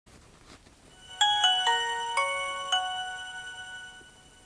zapnuti.mp3